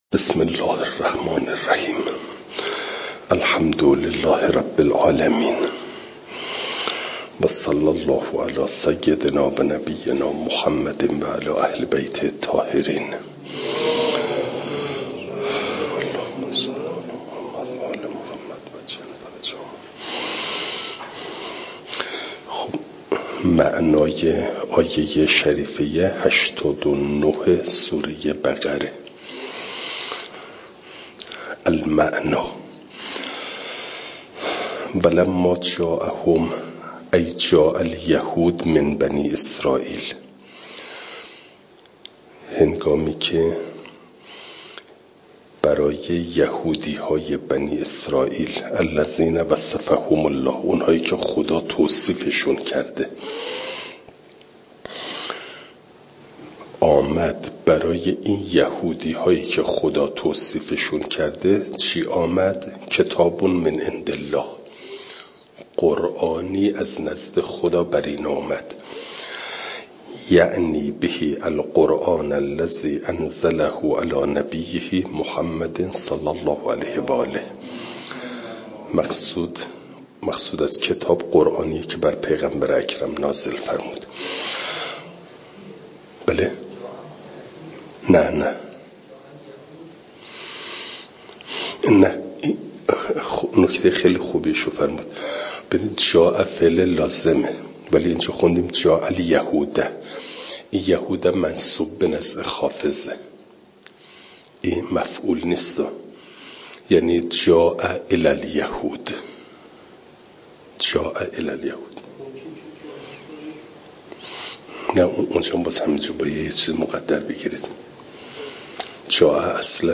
فایل صوتی جلسه نود و ششم درس تفسیر مجمع البیان